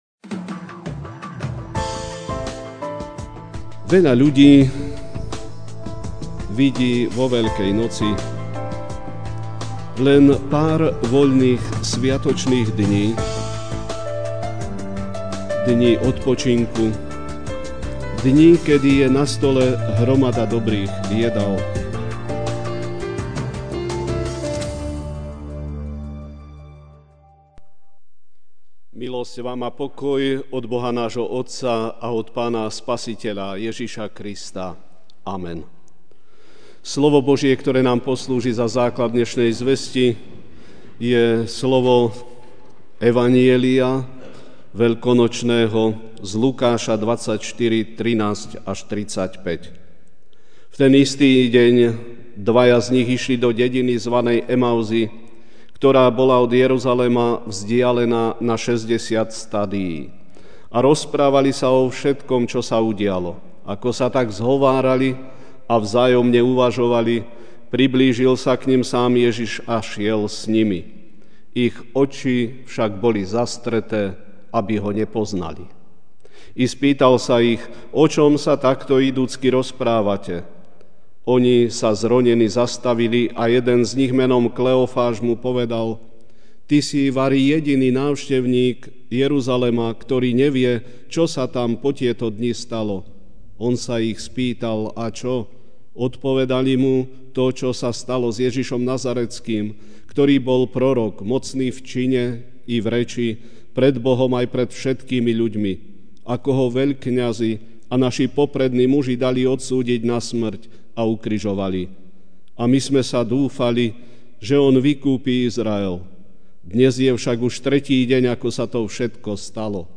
Ranná kázeň: Veľkonočná viera (Lk 24, 13-35) A hľa, v ten istý deň išli z nich dvaja do mestečka, vzdialeného od Jeruzalema na šesťdesiat honov, ktoré sa volalo Emauzy.